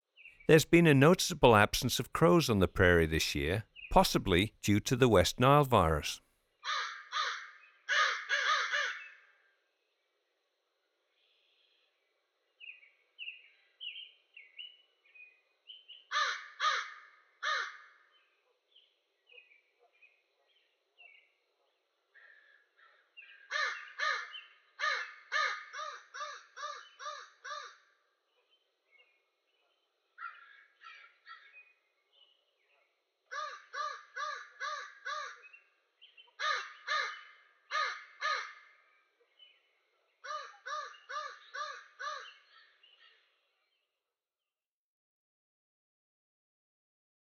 Crow
44-american-crow.m4a